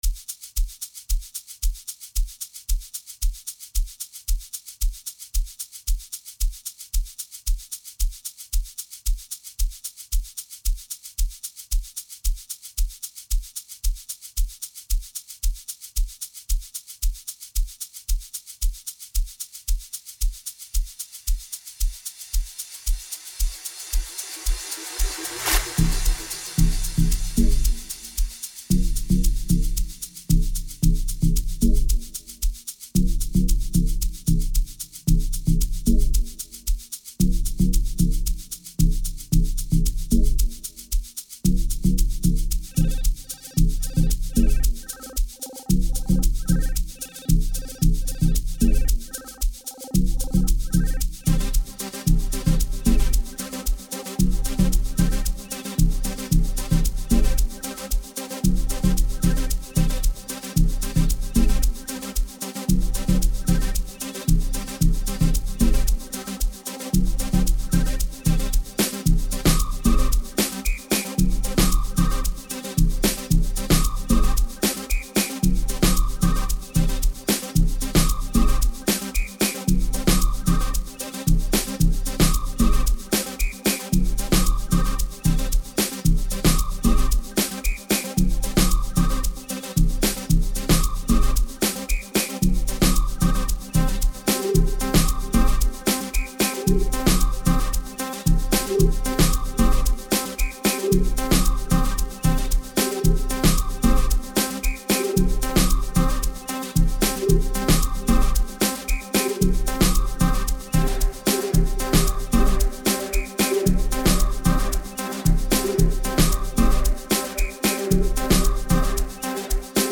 07:13 Genre : Amapiano Size